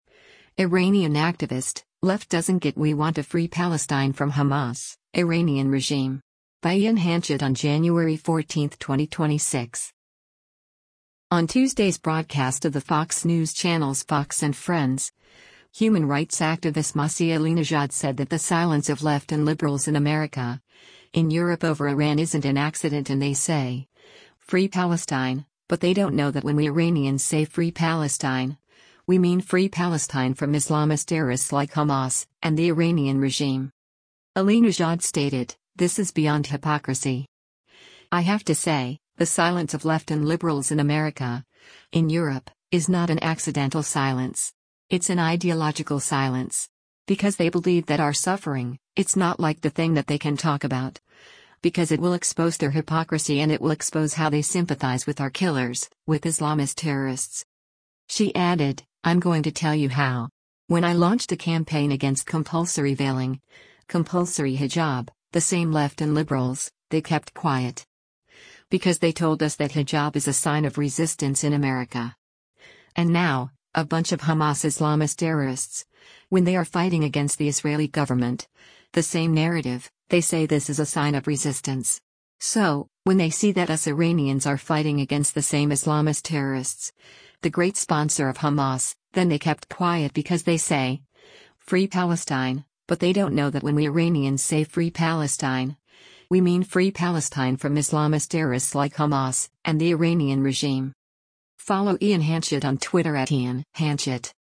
On Tuesday’s broadcast of the Fox News Channel’s “Fox & Friends,” human rights activist Masih Alinejad said that “the silence of left and liberals in America, in Europe” over Iran isn’t an accident and “they say, free Palestine, but they don’t know that when we Iranians say free Palestine, we mean free Palestine from Islamist terrorists like Hamas…and the Iranian regime.”